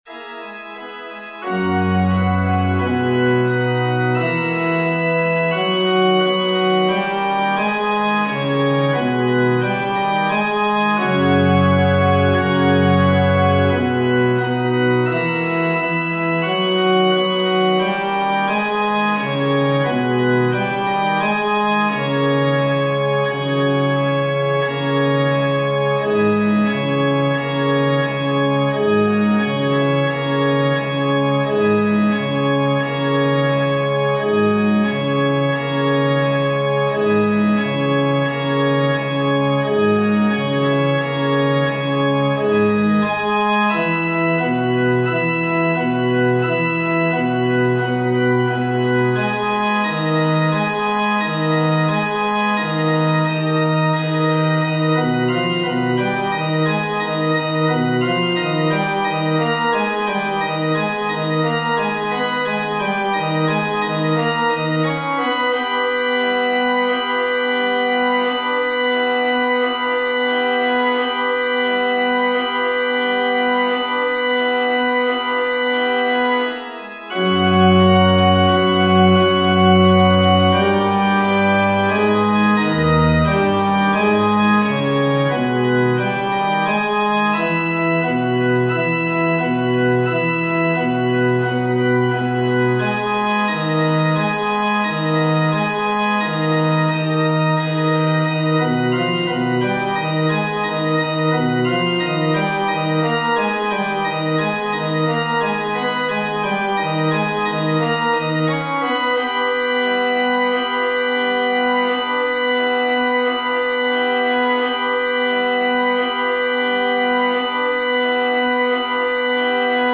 FF:HV_15b Collegium male choir
Rozlouceni-bas.mp3